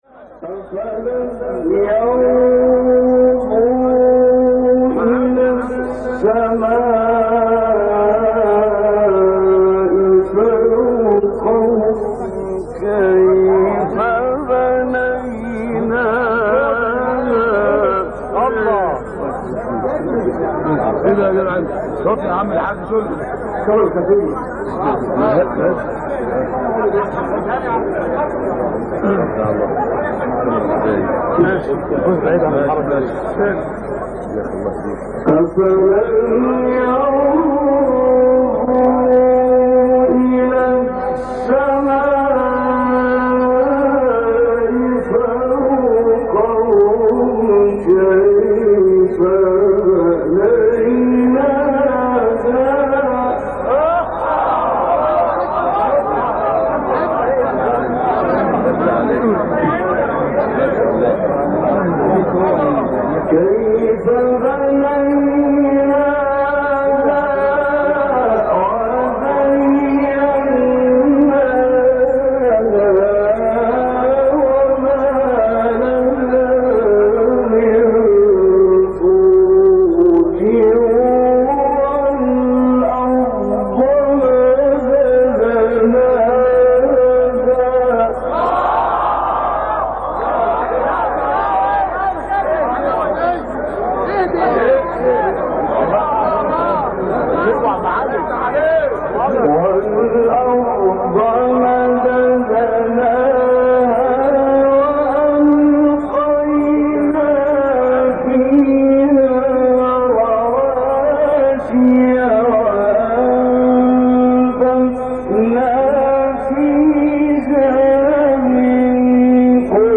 بخشی بسار زیبا از سوره ق با صدای طنطاوی | نغمات قرآن | دانلود تلاوت قرآن